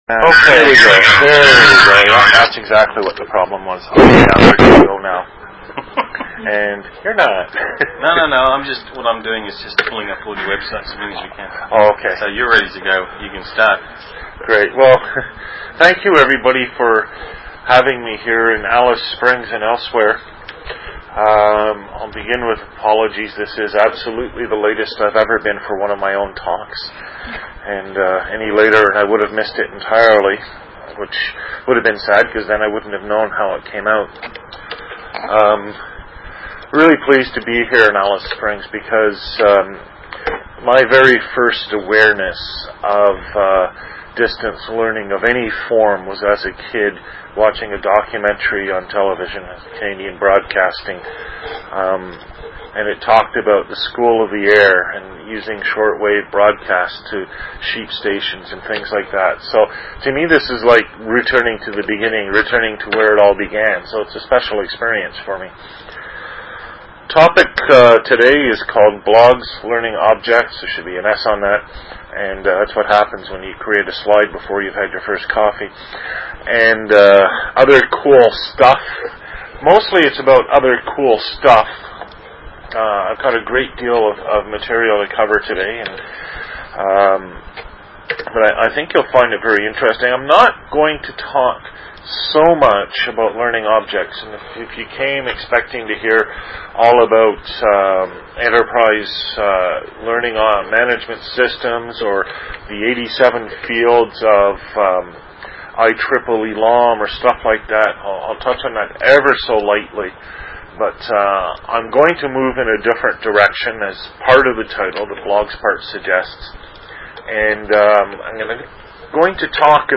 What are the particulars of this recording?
Invited Presentation, School of the Air, Alice Springs, NT, Australia, Seminar, Sept 27, 2004.